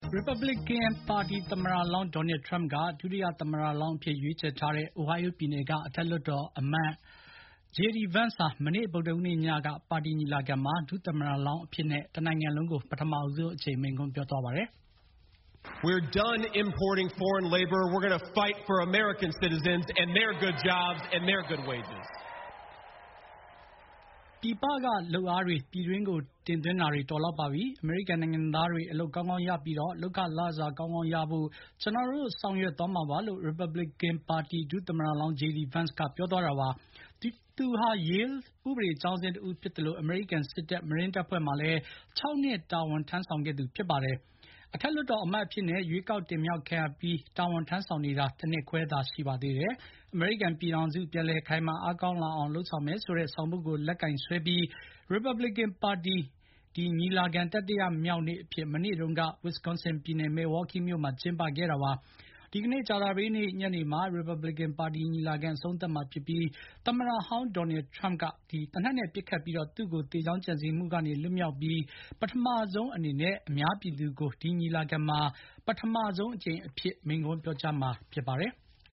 ရီပါဘလစ်ကန်ပါတီ သမ္မတလောင်း Donald Trump က ဒုတိယ သမ္မတလောင်းအဖြစ် ရွေးချယ်ထားတဲ့ Ohio ပြည်နယ်က အထက်လွှတ်တော်အမတ် J.D. Vance ဟာ မနေ့ ဗုဒ္ဓဟူးနေ့က ပါတီညီလာခံမှာ ဒုသမ္မတလောင်းအဖြစ်နဲ့ တနိုင်ငံလုံးကို ပထမဆုံးအကြိမ် မိန့်ခွန်းပြောကြားခဲ့ပါတယ်။